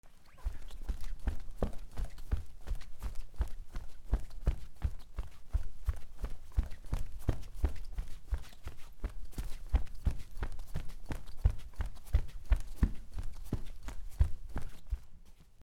畳で走る
/ I｜フォーリー(足音) / I-180 ｜足音 畳